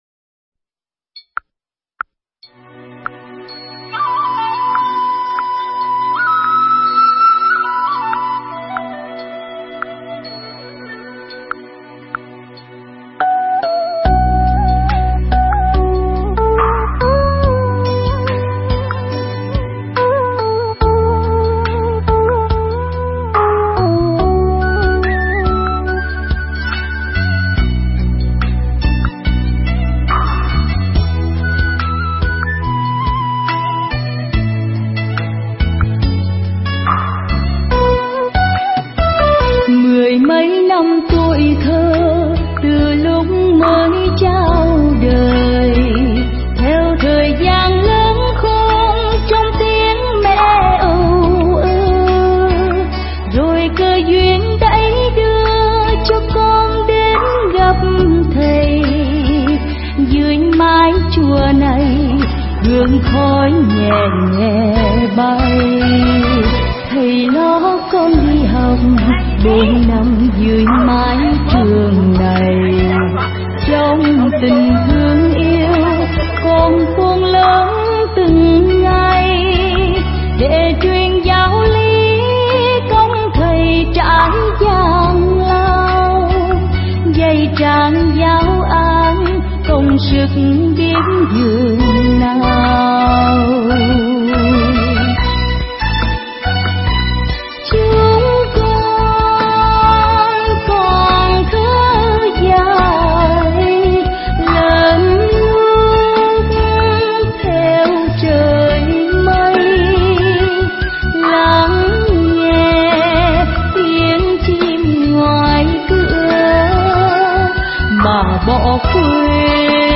thuyết giảng tại Chùa Tịnh Độ Phổ Đà Sơn